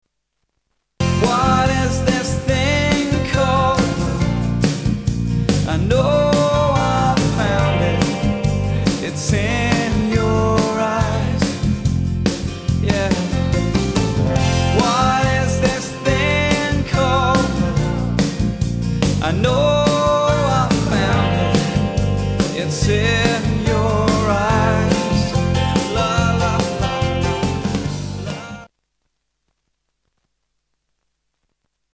STYLE: Rock
snappy '90s pop-rock